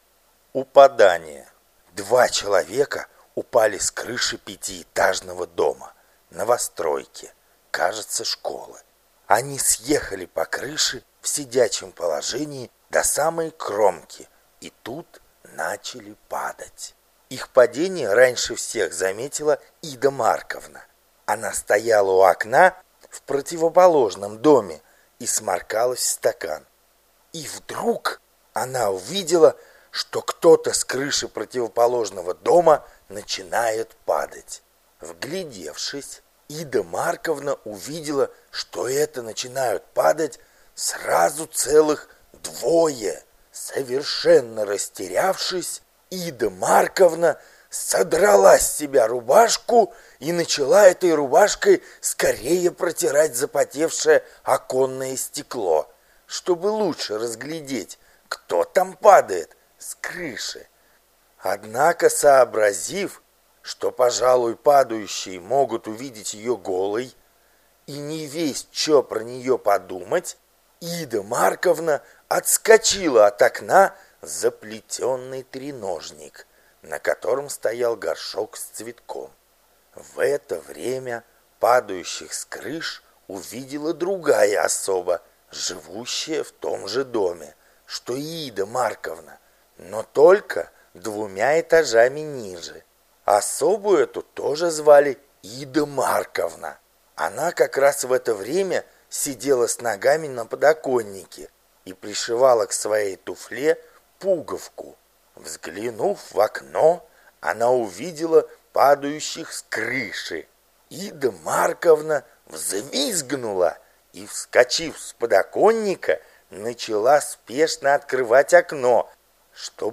Галерея Хармс 5 часов АУДИОКНИГА